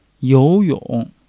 you2-yong3.mp3